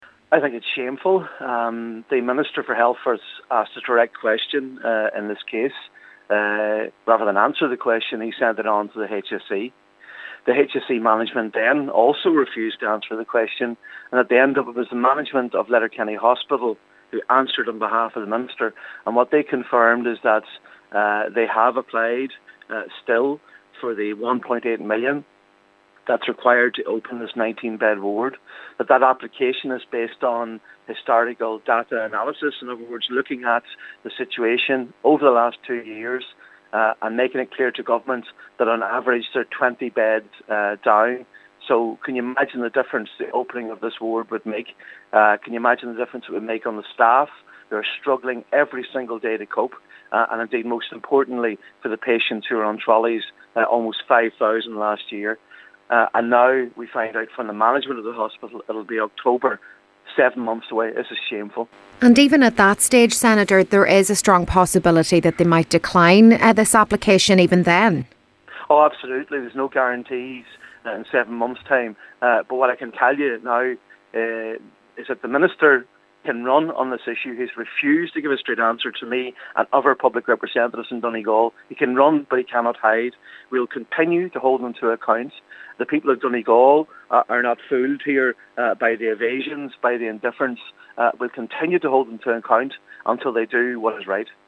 Senator MacLochlainn says a seven month delay for a decision is completely unacceptable: